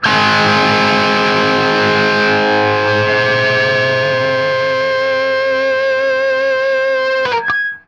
TRIAD G#  -L.wav